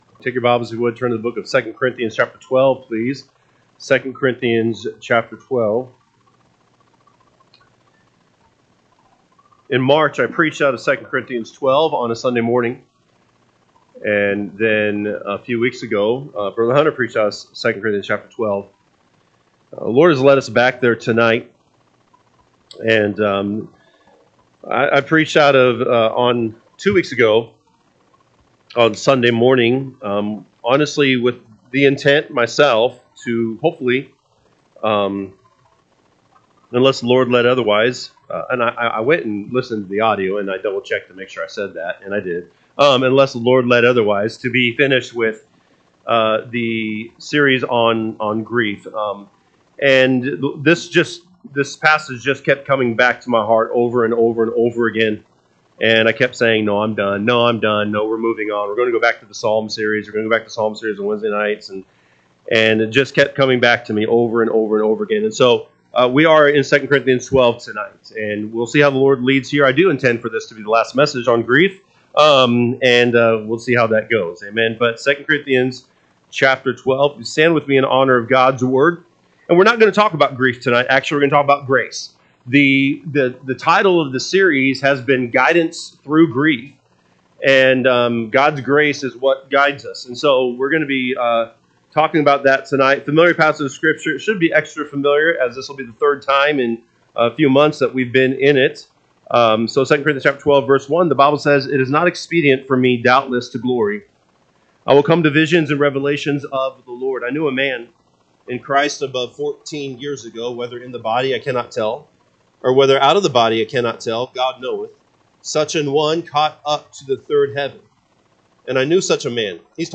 Wednesday Evening